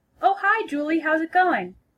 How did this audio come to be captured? This conversation is an example of informal telephone English.